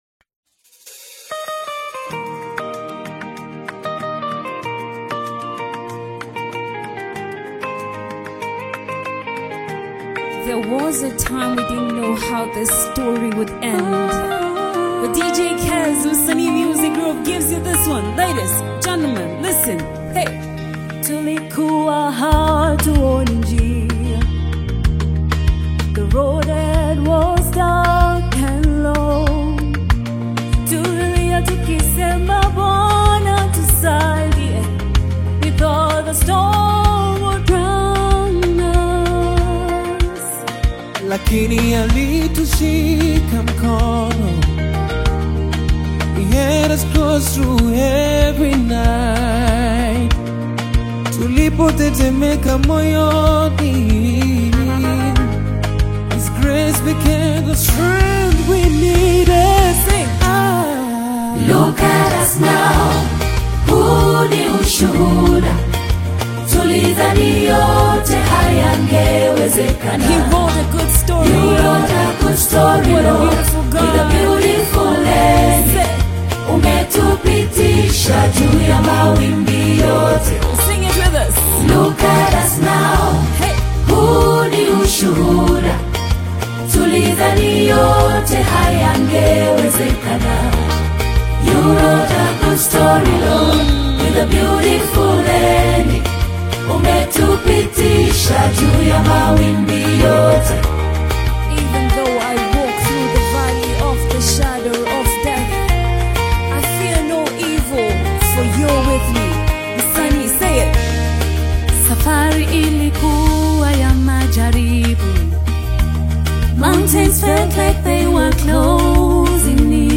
uplifting gospel/Afro-spiritual single